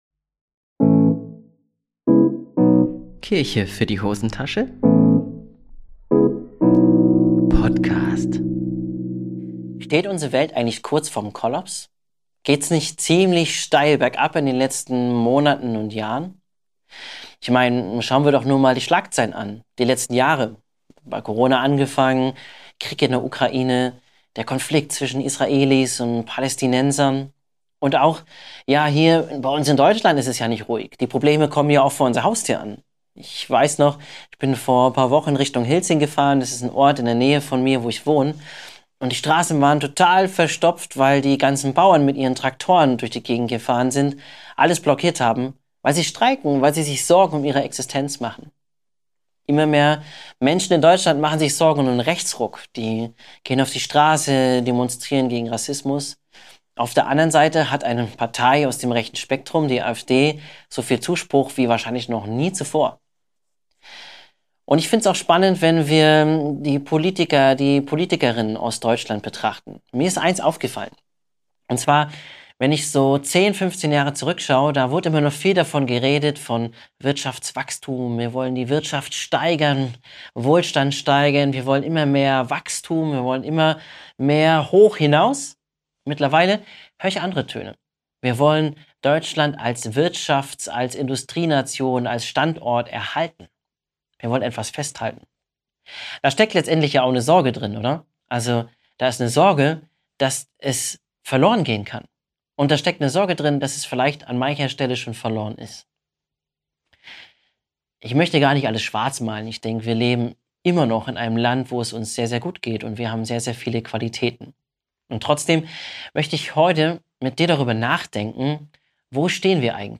Ist unser System und unser Lebensstil am Ende? Was braucht es für eine lebenswerte Zukunft? Ich wage mich in diesem Vortrag an eine kleine Analyse unserer Lage und versuche Werte zu ermitteln, die uns als Basis für eine lebenswerte Zukunft dienen könnten.